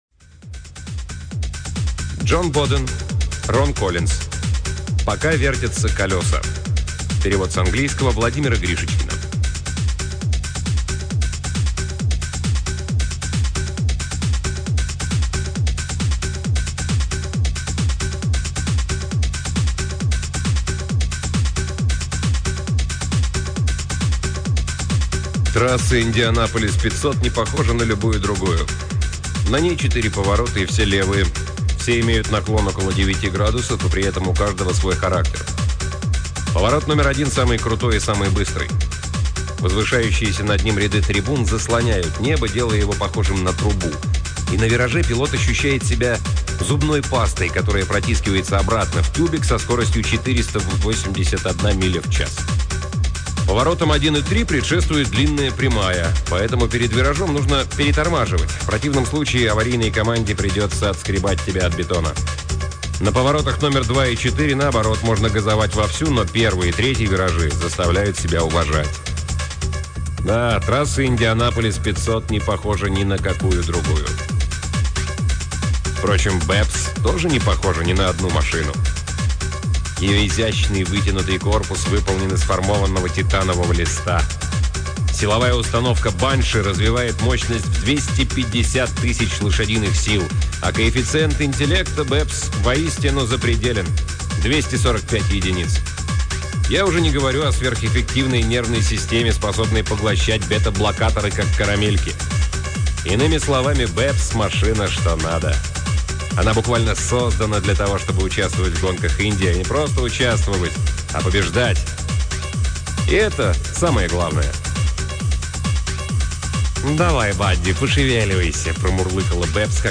Аудиокниги передачи «Модель для сборки» онлайн